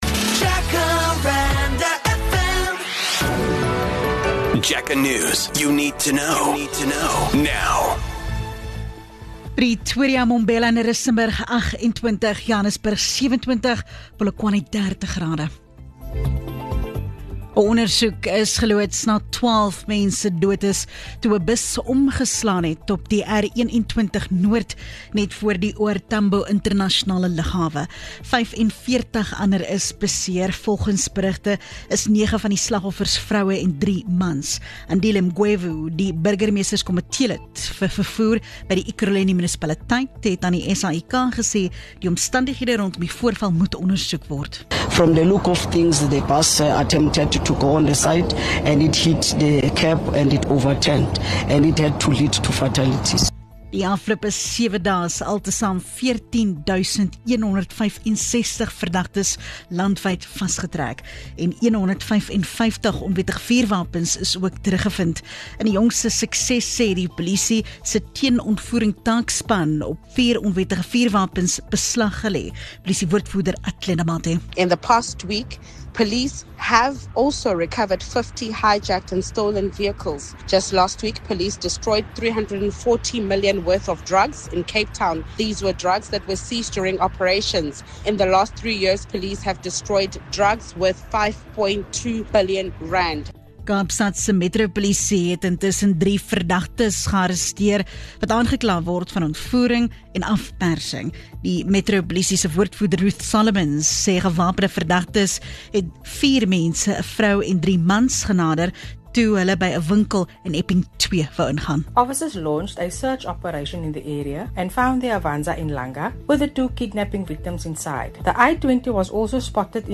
The Jacaranda FM News team is based in Gauteng – but covers local and international news of the day, providing the latest developments online and on-air. News bulletins run from 5am to 7pm weekdays, and from 7am to 6pm on weekends and public holidays.